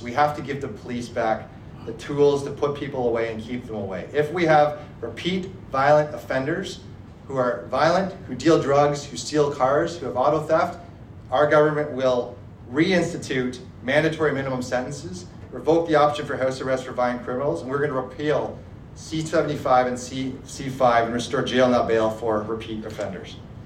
Williams was the guest speaker at Wednesday morning’s Belleville Chamber of Commerce breakfast held at Sans Souci in the Downtown District.